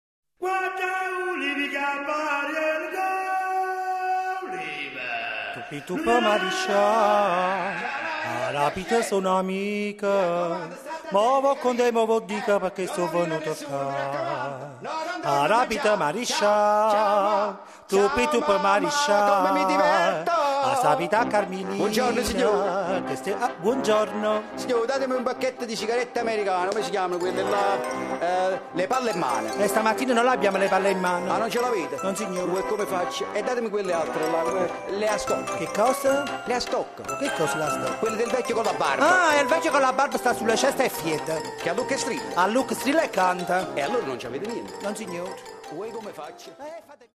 Cantanti e narratori:
brevi sketches teatrali rigorosamente in dialetto napoletano